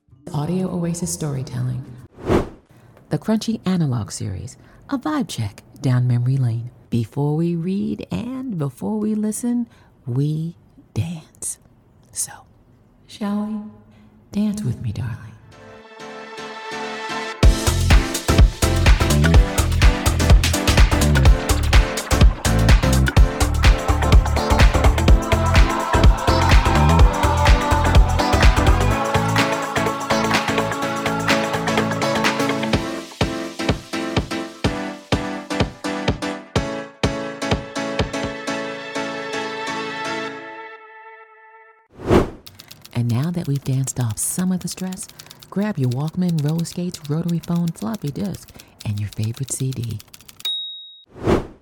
Middle Aged